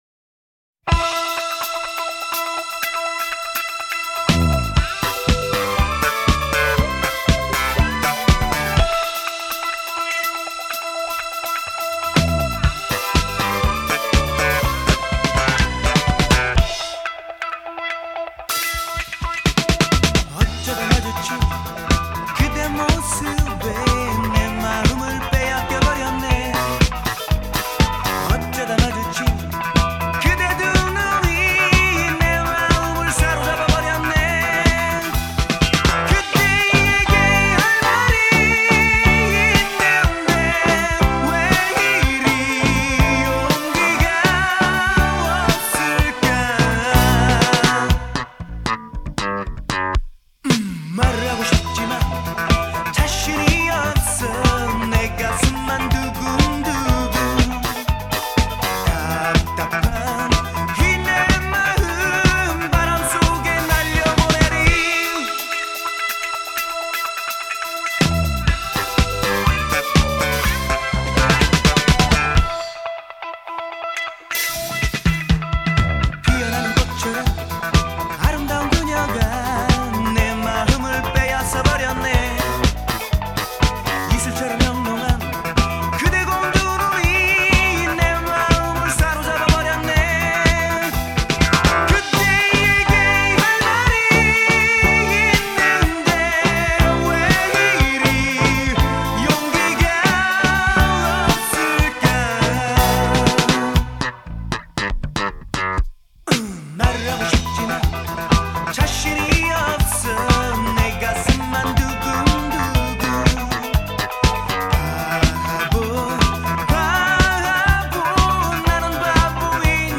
گروه راک کره ای...